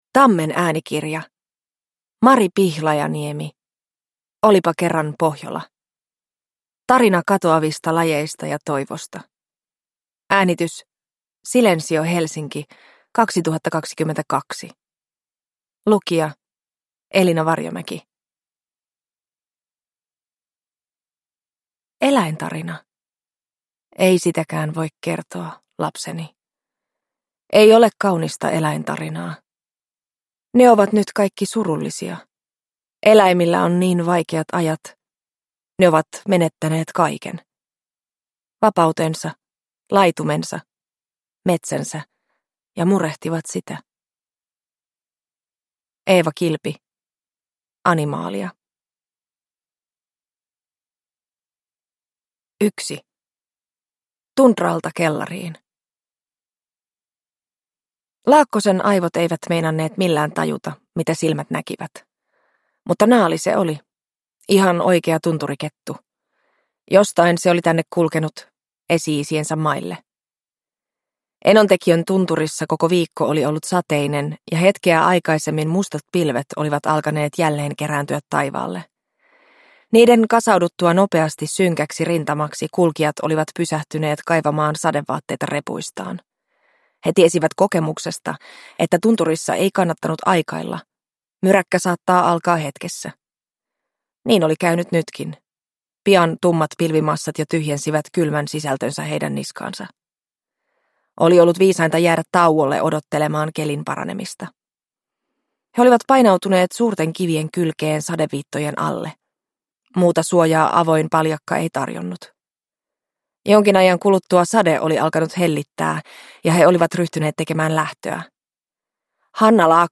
Olipa kerran Pohjola – Ljudbok – Laddas ner